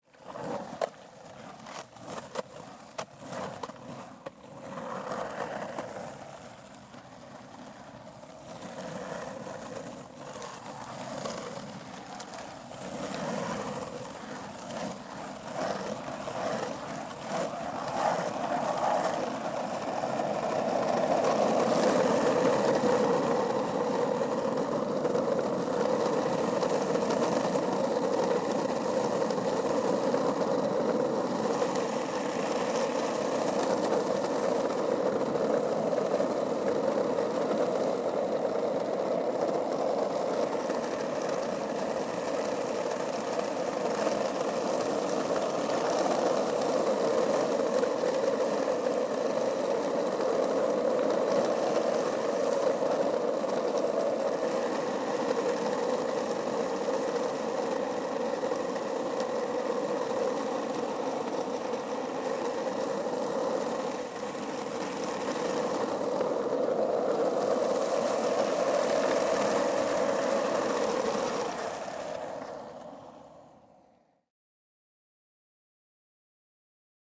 Звуки скейтборда
Звук долгого катания на лонгборде или скейтборде